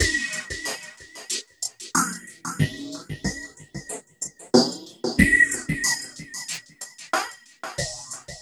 CHUG SWEEP-R.wav